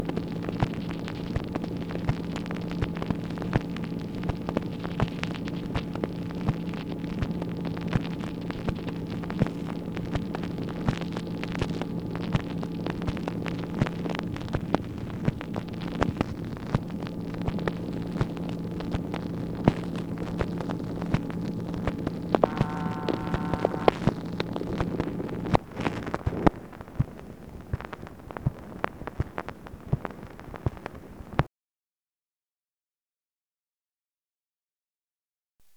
MACHINE NOISE, September 18, 1964
Secret White House Tapes